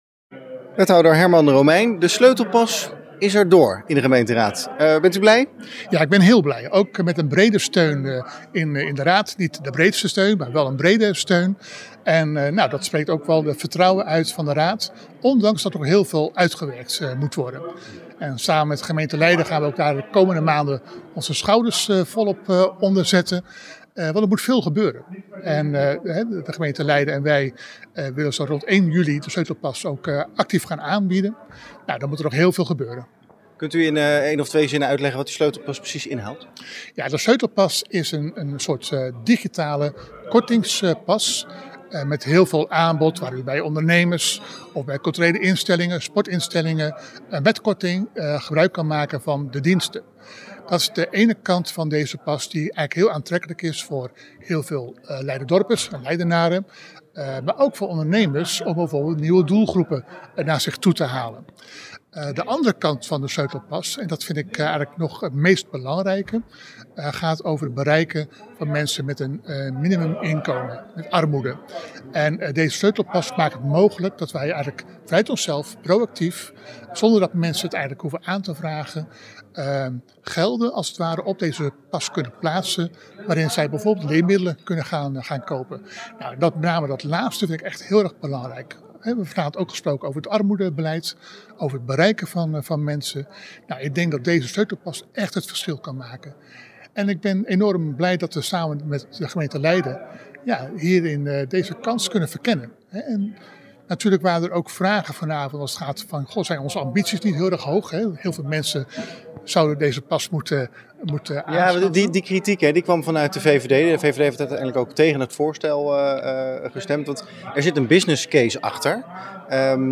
Wethouder Herman Romeijn over de Sleutelpas.